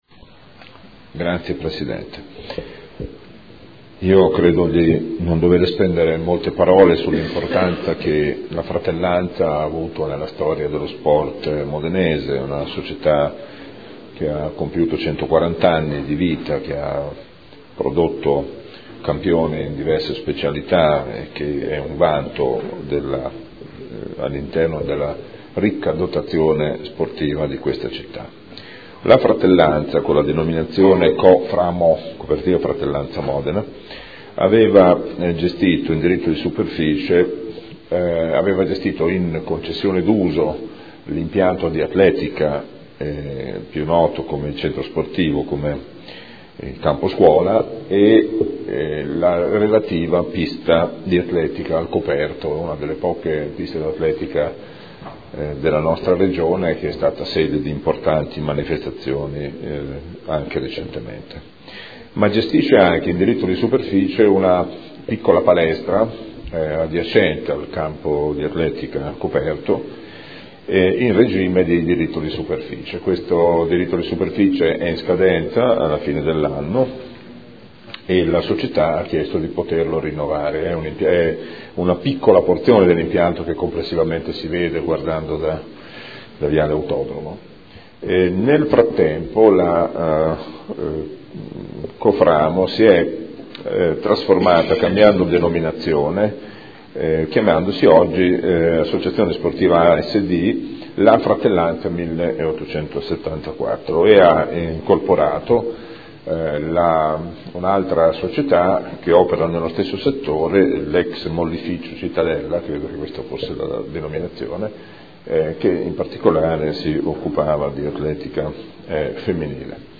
Gabriele Giacobazzi — Sito Audio Consiglio Comunale